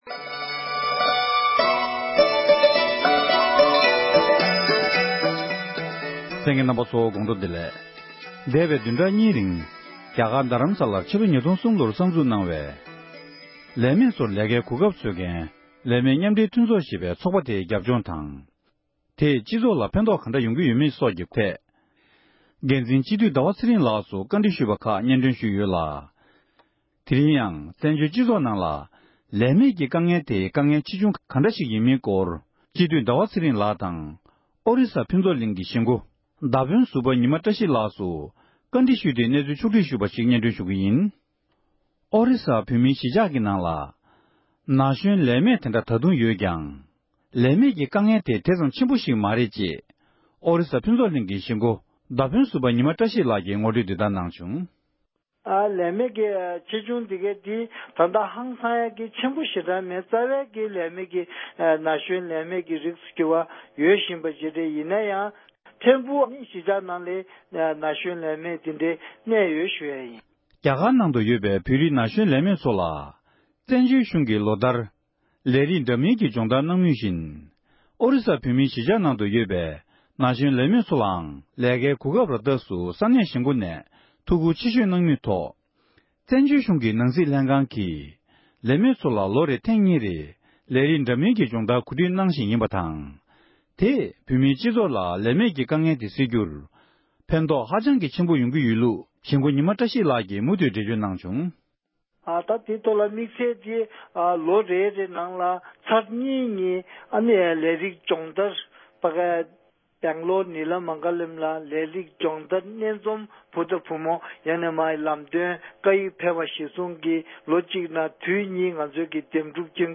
འབྲེལ་ཡོད་མི་སྣ་ཁག་ཅིག་ལ་གནས་འདྲི་ཞུས་པར་གསན་རོགས༎